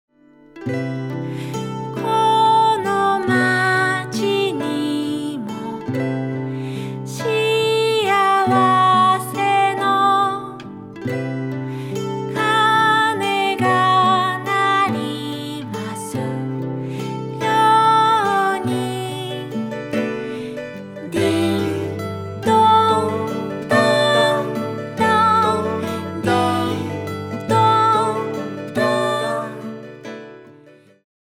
シンプルで温かいサウンドはそのままに、ついつい口ずさみ、踊りだしたくなっちゃう魔法の三人組。